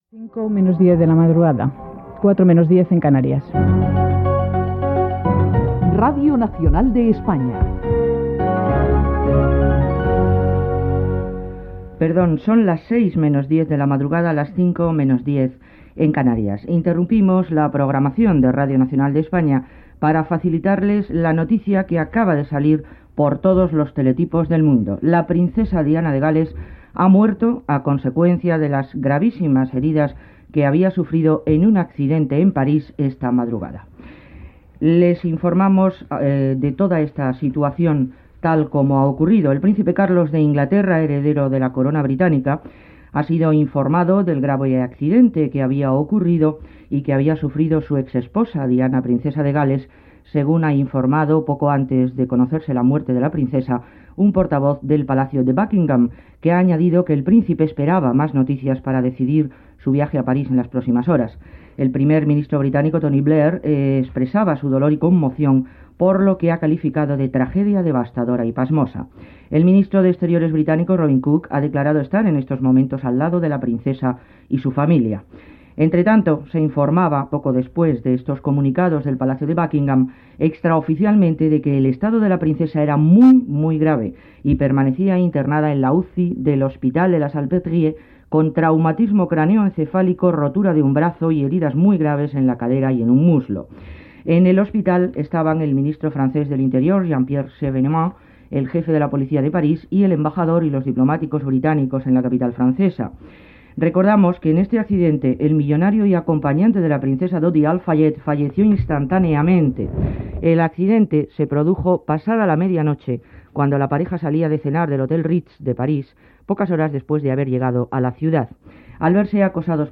Indicatiu, hora, notícia de la mort de la princesa Diana de Gal·les (Diana Frances Spencer ) com a conseqüència de l'accident de trànsit que havia tingut a París.
Informatiu